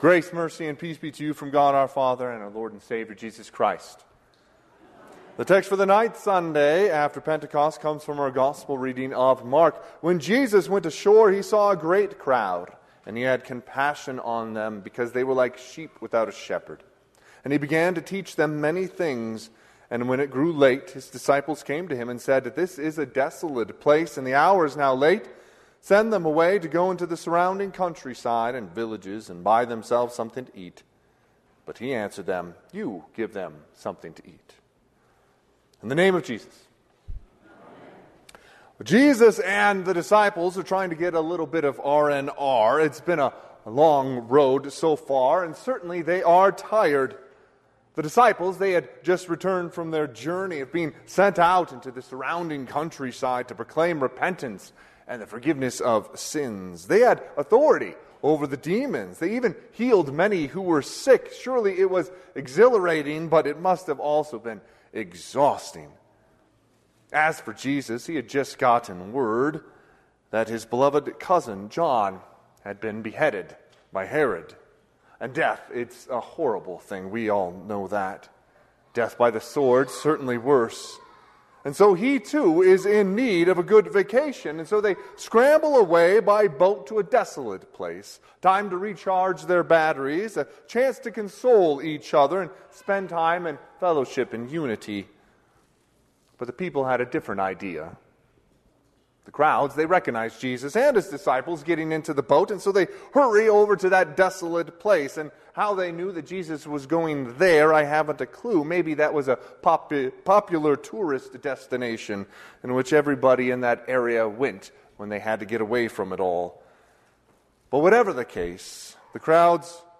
Sermon - 7/21/2024 - Wheat Ridge Lutheran Church, Wheat Ridge, Colorado
Ninth Sunday after Pentecost